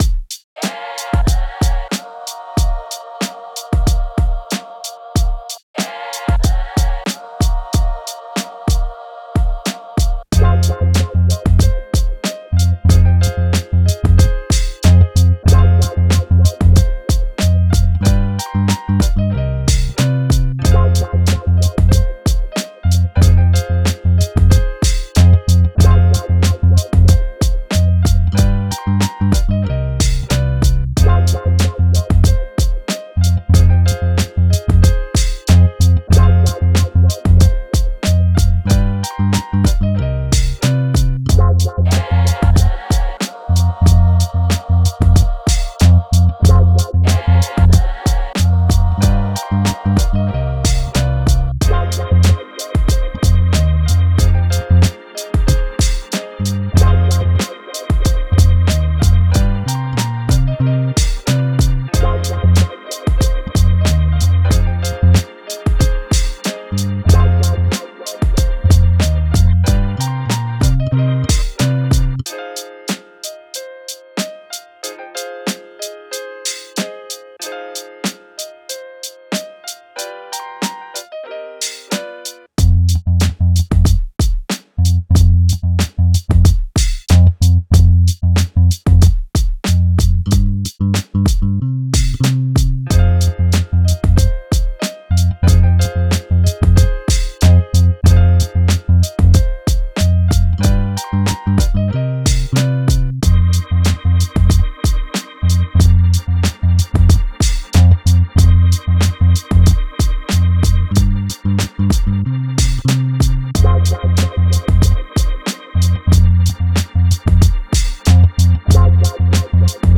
怪しい雰囲気のピアノの旋律にBoombapらしいビート、ラップを乗せれば渋くていなたい曲が完成すこと間違いなし。
BPM93 Key Cm 構成 イントロ4 バース16 フック8 間奏4 バース16 フック16 アウトロ4